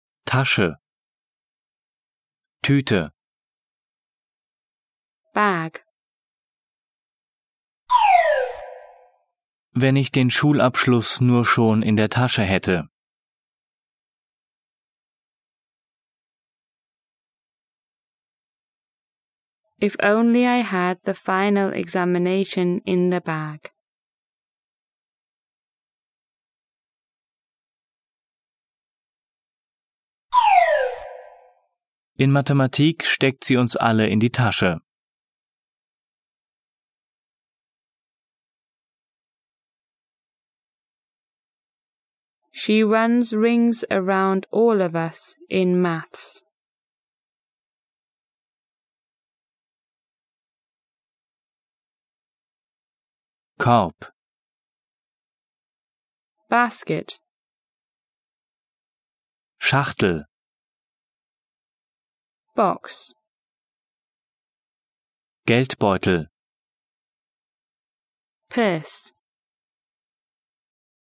von Muttersprachlern gesprochen
mit Übersetzungs- und Nachsprechpausen
Er ist zweisprachig aufgebaut (Deutsch - Englisch), nach Themen geordnet und von Muttersprachlern gesprochen.